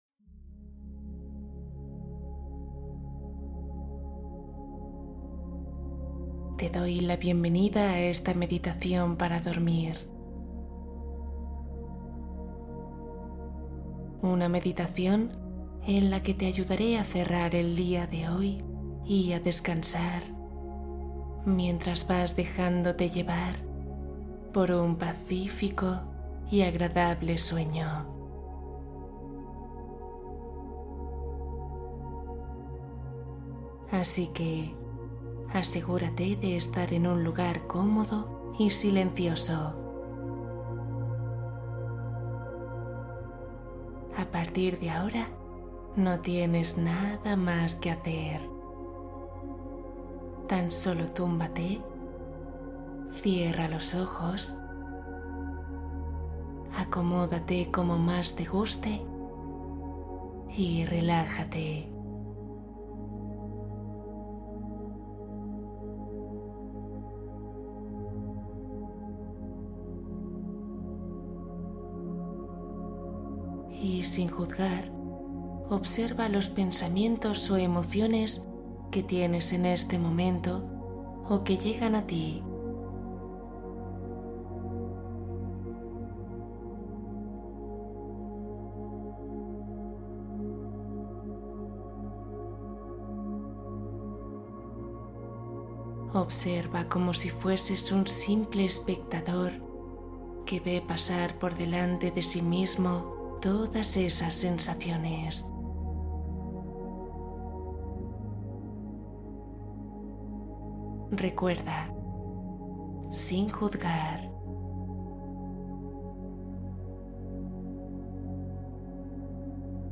Libera tus miedos ❤ Meditación para calmar inseguridades